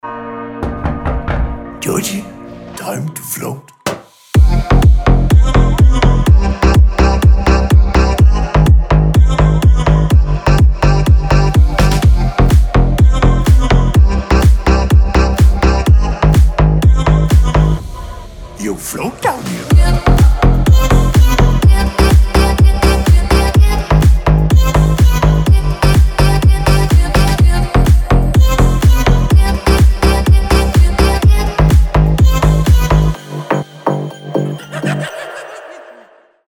EDM
future house
пугающие
electro house
смех
страшные
стук
жуткие